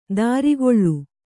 ♪ dārigoḷḷu